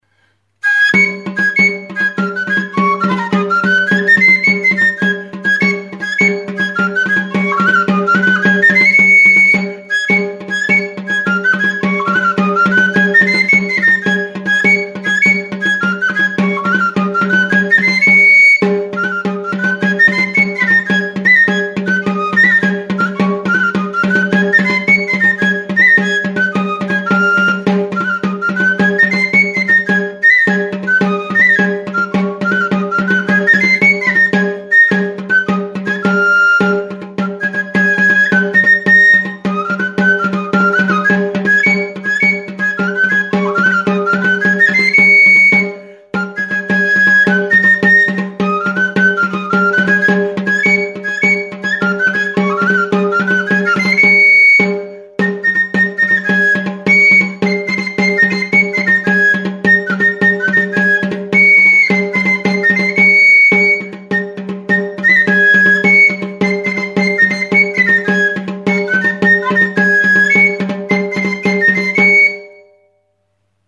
TXISTUA; TXISTU METALIKOA | Soinuenea Herri Musikaren Txokoa
Aerophones -> Flutes -> Fipple flutes (one-handed)
Recorded with this music instrument.
3 zuloko flauta zuzena da, metalezko txistua (nikelatua eta gero kromatua). 3 zatitan egina dago, fa # tonuan afinaturik.